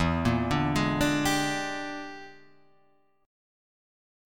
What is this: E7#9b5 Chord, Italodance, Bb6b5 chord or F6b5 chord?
E7#9b5 Chord